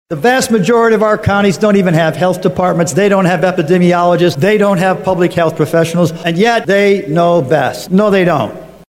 House Democratic Leader Frank Dermody (D-Allegheny/Westmoreland) talks about his opposition to the bill.